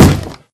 Sound / Minecraft / mob / zombie / wood1.ogg